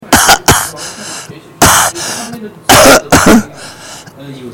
干咳.mp3